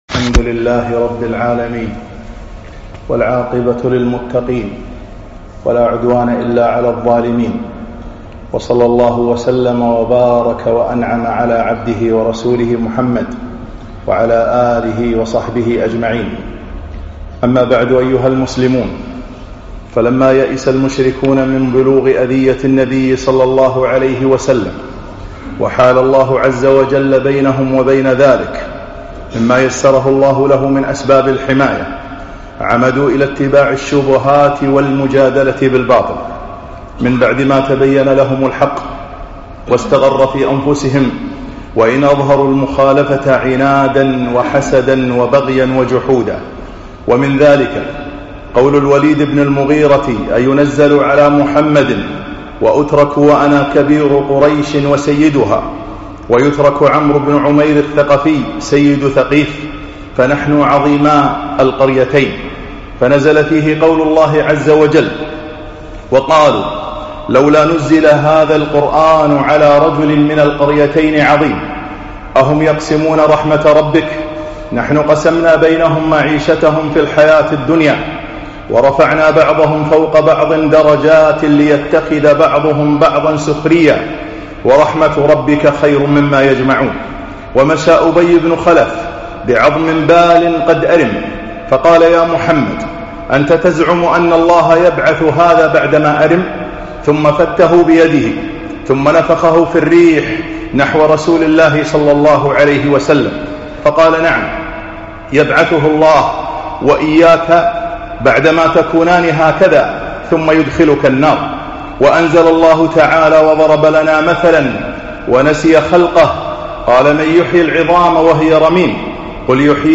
خطب السيرة النبوية 6